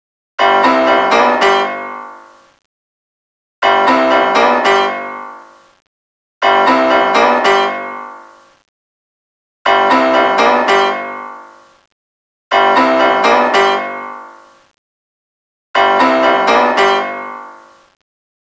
Funny sound effects
piano_riff.wav